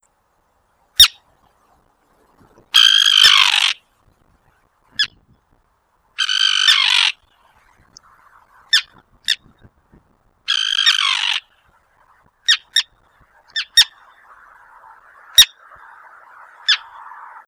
GAVIOTÍN ÁRTICO
gaviotinartico.wav